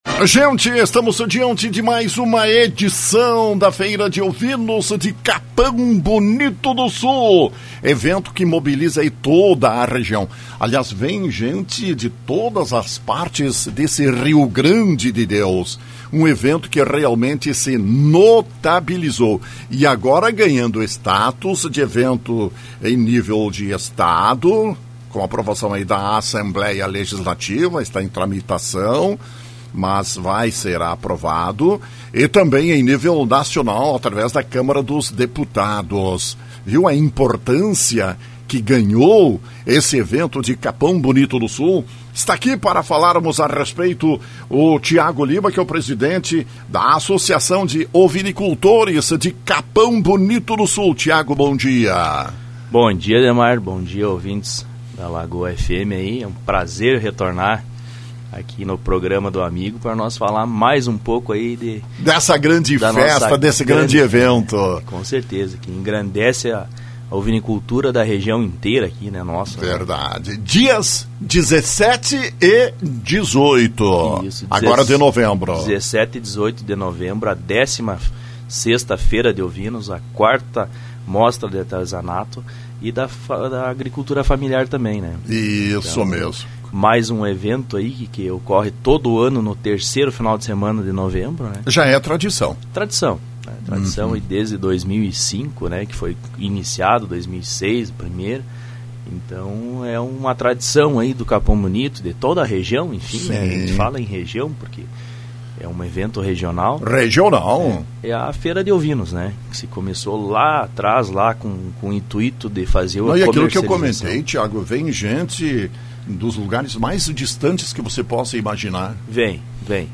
em entrevista à Rádio Lagoa FM.